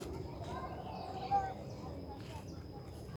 Tropical Screech Owl (Megascops choliba)
Detailed location: Parque San Carlos
Condition: Wild
Certainty: Observed, Recorded vocal